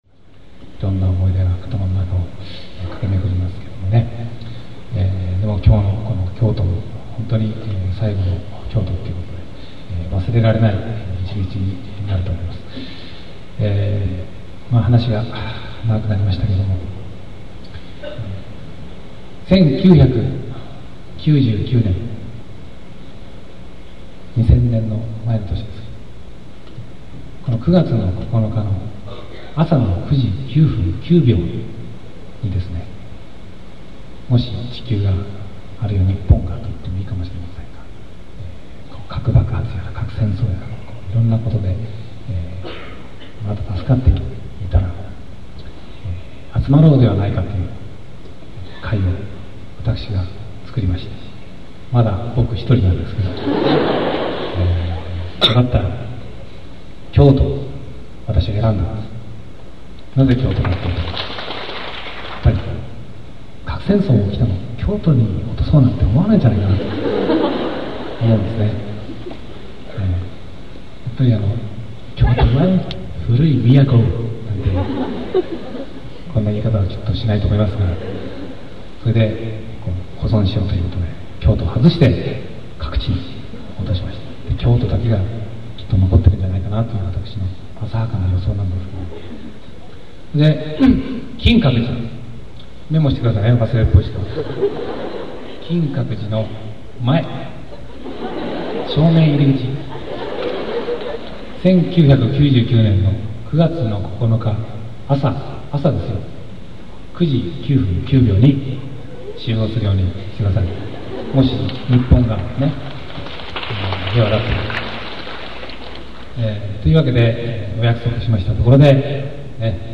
それはさておき、先日カセットテープを整理していたら、財津和夫(*2)がライブ中に
ここで公開するのは1989年4月12日の京都公演での金閣寺の約束の発言の音源です。
チューリップ・ファイナル・ツアー"Well" 京都会館第一ホール